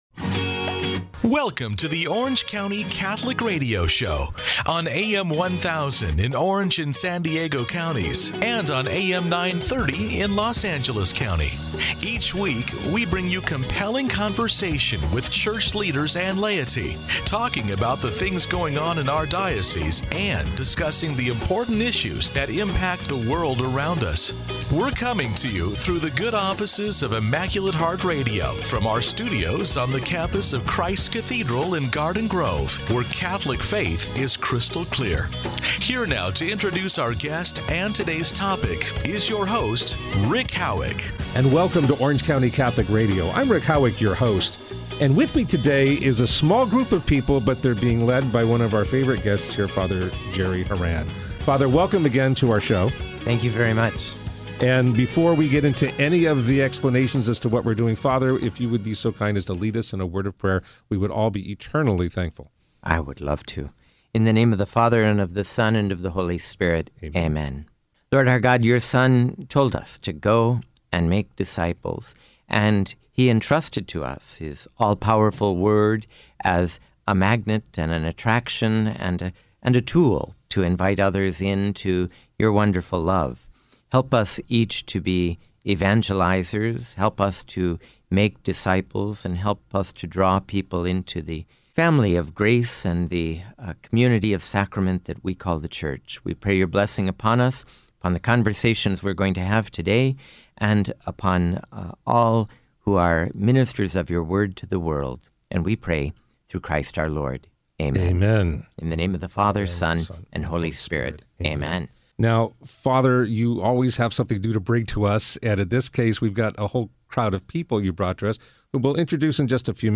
interviews guests on a variety of topics.